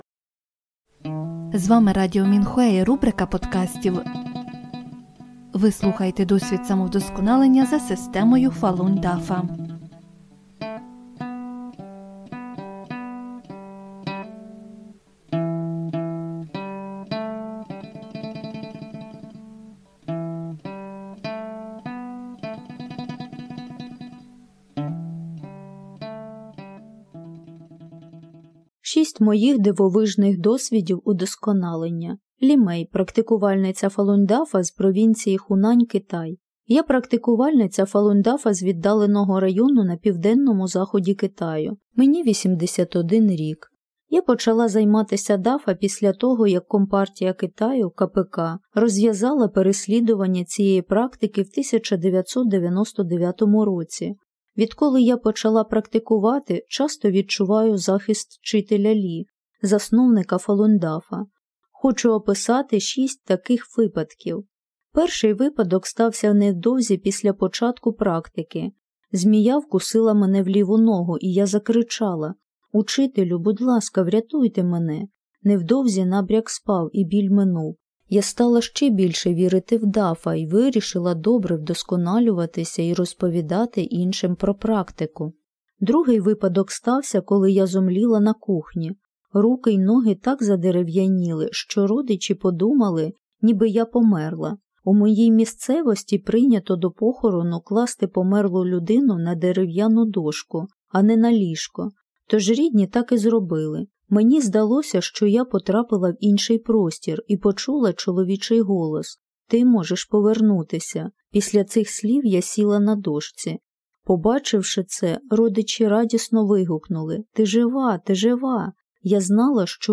Музику з подкастів написали й виконали практикувальники Фалунь Дафа.